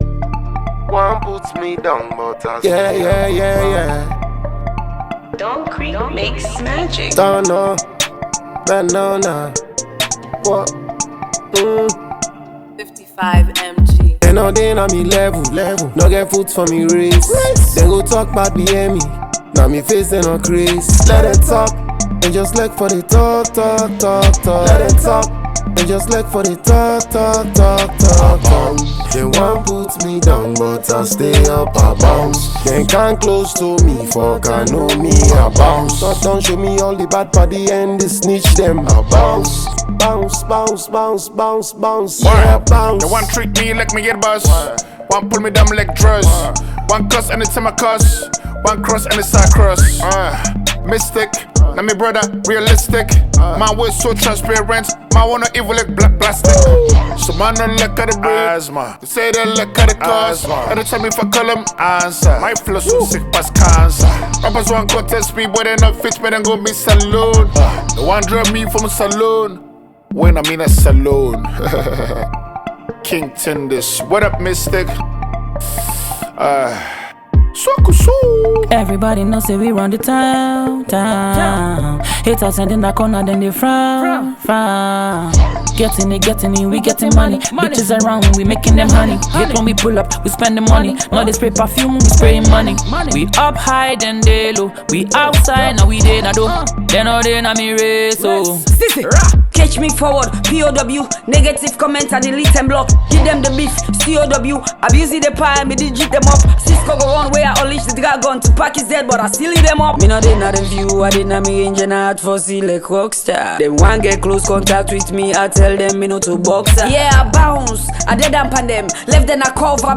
fun drill type song filled with catchy punchlines